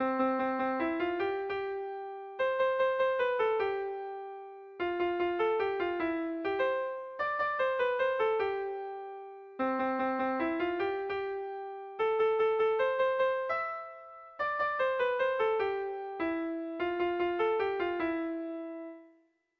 Air de bertsos - Voir fiche   Pour savoir plus sur cette section
Sentimenduzkoa
Zortziko ertaina (hg) / Lau puntuko ertaina (ip)
ABA2A3